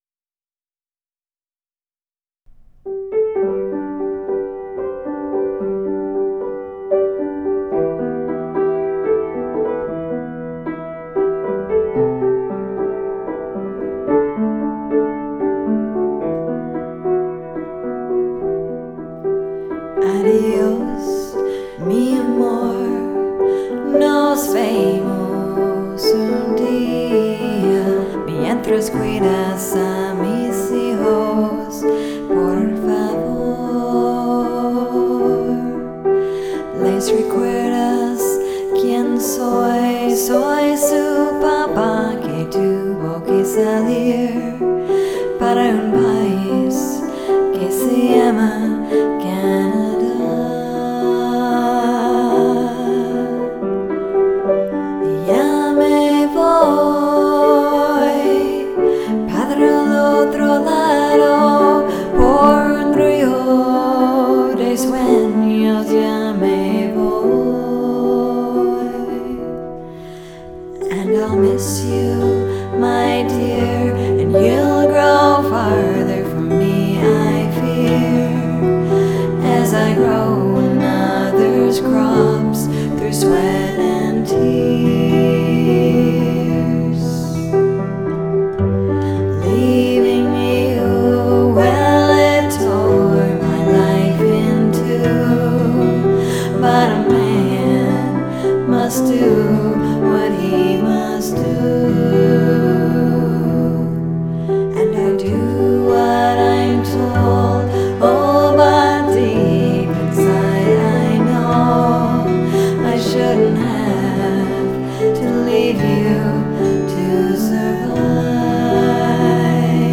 Piano y guitarra
Chelo